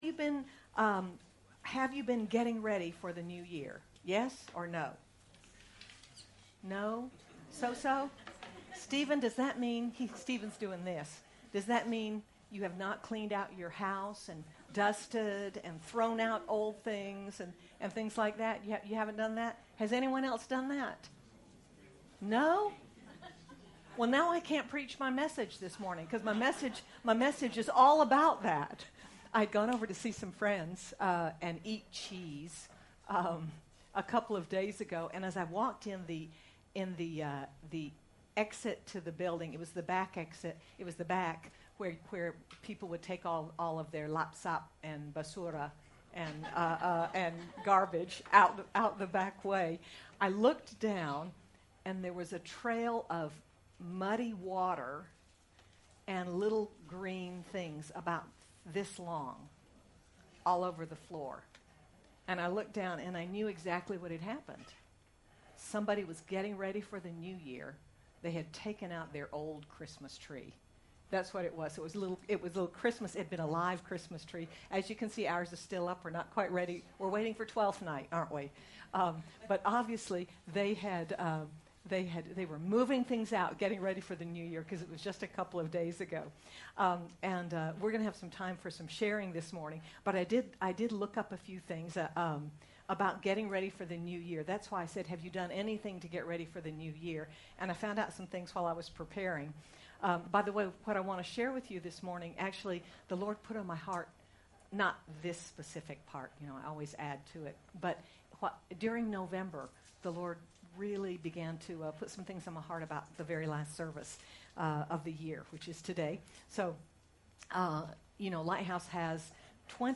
Are we entering 2024 with hearts clean and ready for the good things God has in store for us? Sermon by